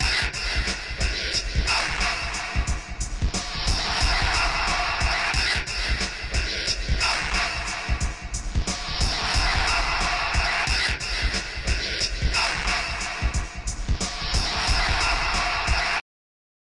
描述：嘻哈呃呃唱
标签： 回声 节拍 人声 奇怪 臀部 吓人
声道立体声